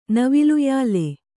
♪ naviluyāle